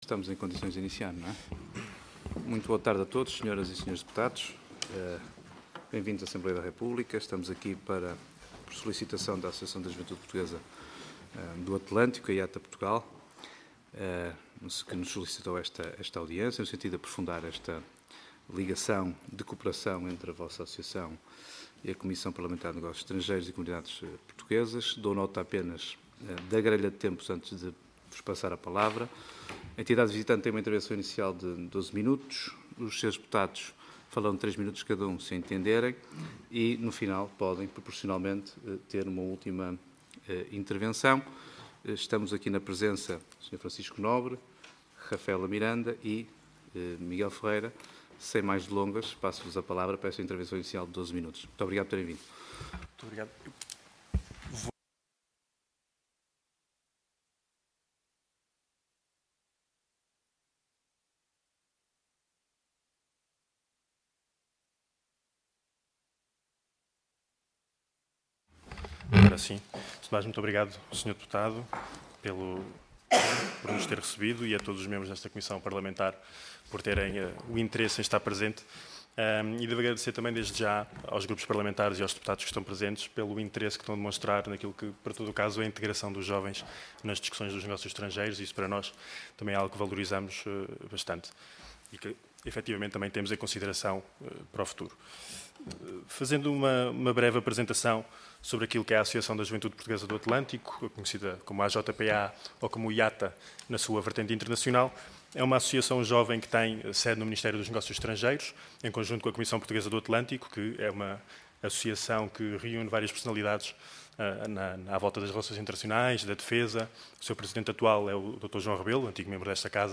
Comissão de Negócios Estrangeiros e Comunidades Portuguesas Audiência Parlamentar
Entidades recebidas Associação da Juventude Portuguesa do Atlântico